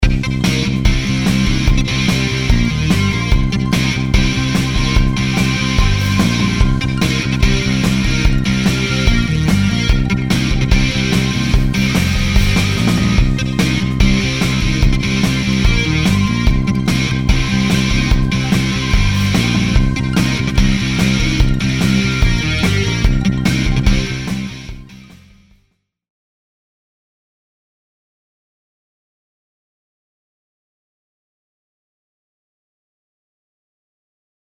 デモ音源におけるパート内訳は、Omnisphereを3トラック使用して作成したシンセパッドのパート、Trilianによるエレクトリックベースのパートに加えて、ドラムパートにToontrackのSuperior Drummer 3、ディストーションギター風のシンセリード音色パートにReveal SoundのSpireという他社製2製品を敢えて加えた構成です。
それぞれのソフト音源は、特にEQ調整や他のプラグインなどを使用しない出音のままの状態でボリュームバランスとパン調整のみを行なっただけですが、FX-Omnisphereを使用しただけで概ねサウンドの質感がまとまります。
FX-Omnisphere_On.mp3